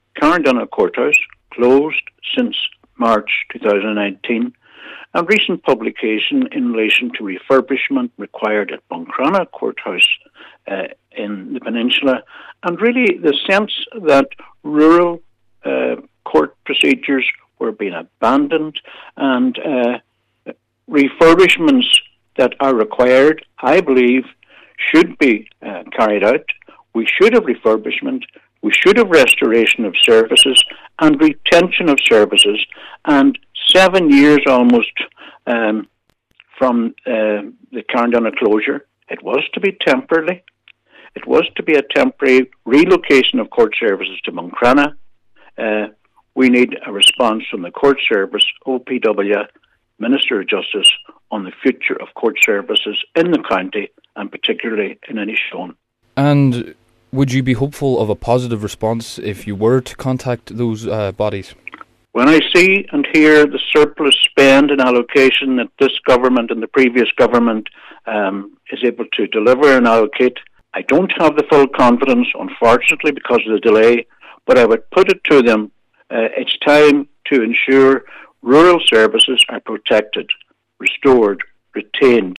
Cllr Albert Doherty says he is calling for rural services to be protected: